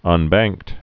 (ŭn-băngkt)